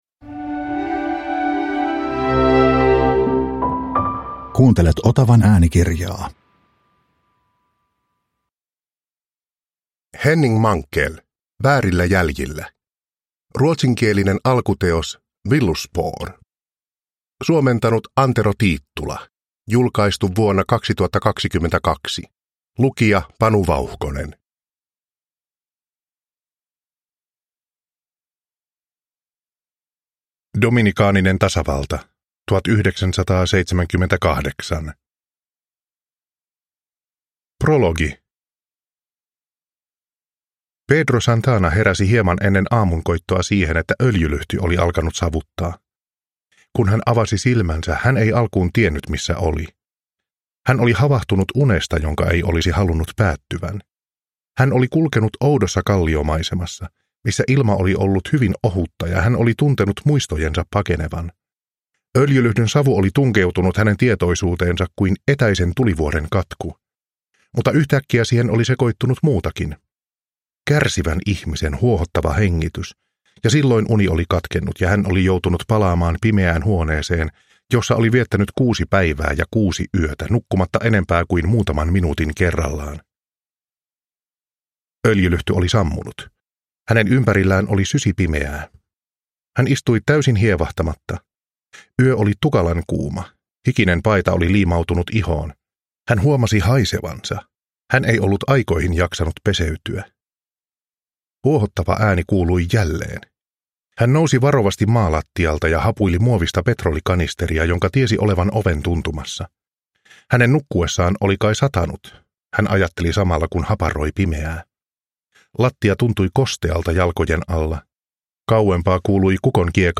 Väärillä jäljillä – Ljudbok – Laddas ner